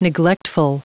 Prononciation du mot neglectful en anglais (fichier audio)
Prononciation du mot : neglectful